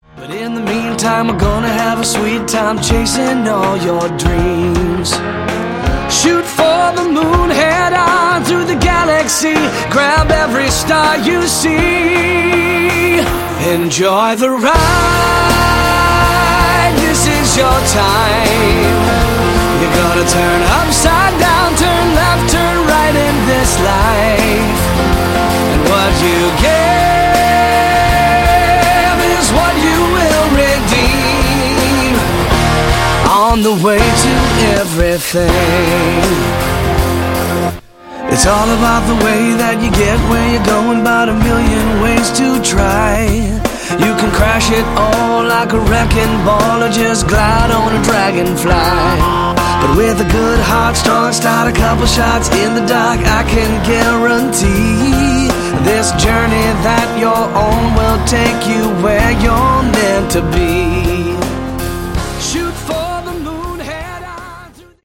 Category: AOR
vocals
keyboards
guitar
drums
bass